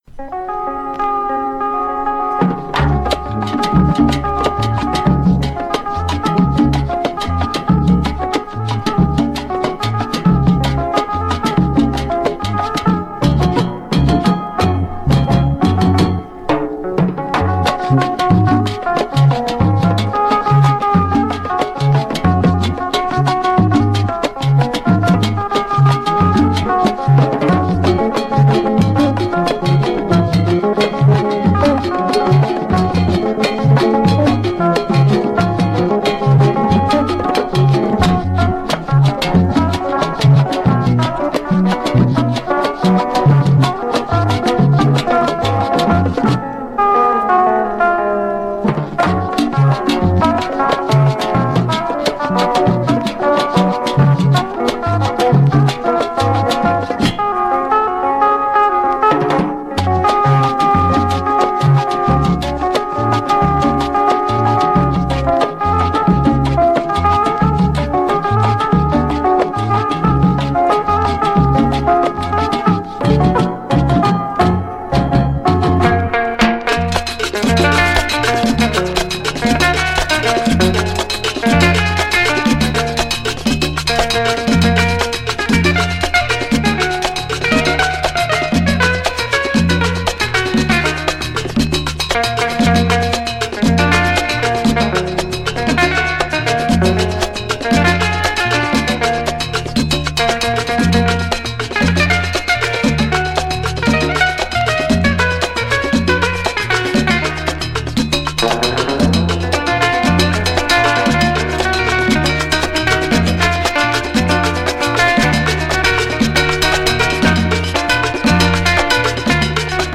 lead guitar
congas
provide some of the most high-energy cumbia sounds
Here is a mix to give you a taste: